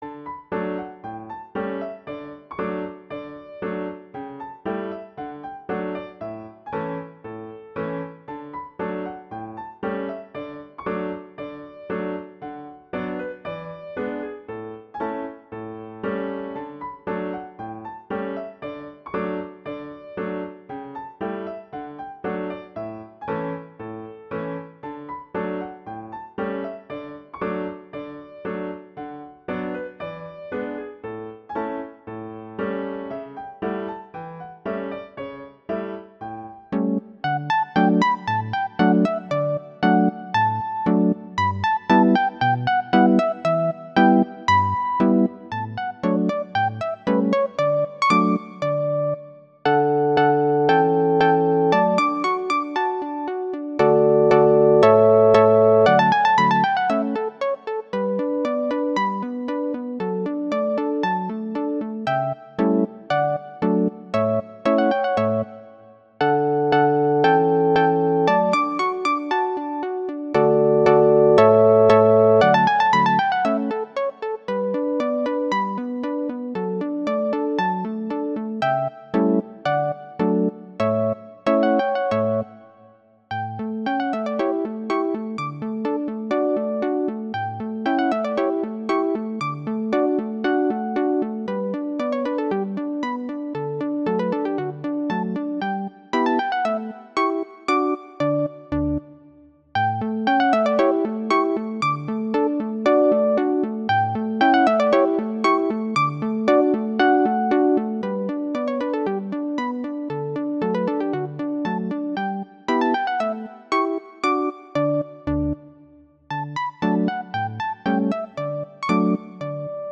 classical, french, children
D major
♩=116 BPM